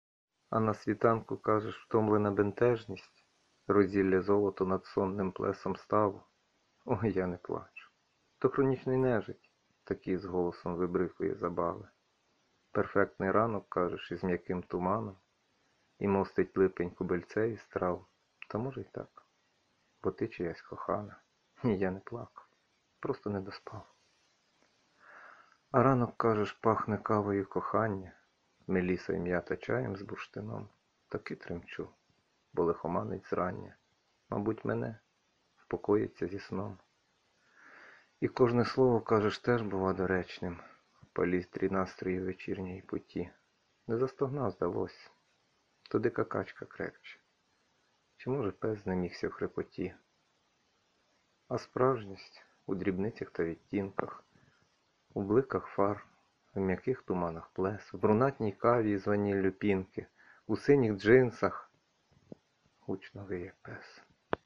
ВИД ТВОРУ: Вірш
Чогось я завжди думала, що у вас грубший голос, але приємно вражена 22
Приємний голос smile
Приємно, мабуть, засипати під його переливи.
Мені дуже до вподоби цей твір, а в поєднанні з Вашим космічним голосом це щось Неймовірне! 16